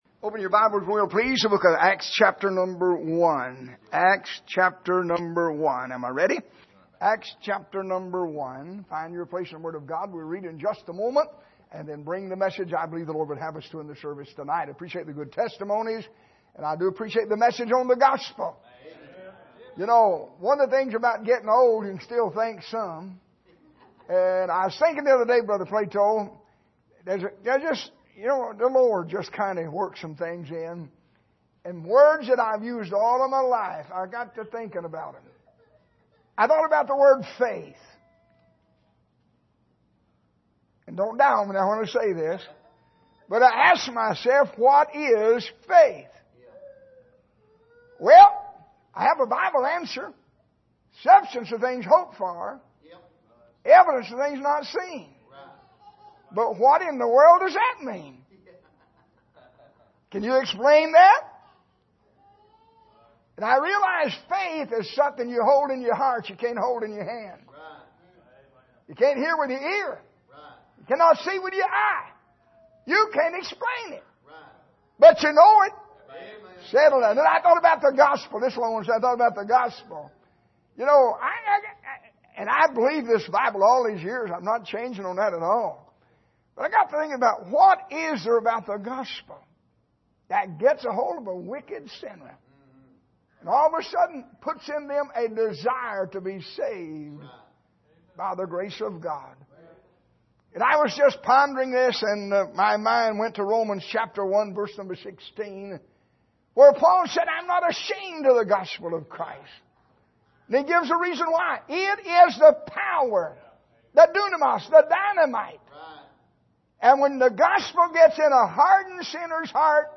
Service: Missions Conference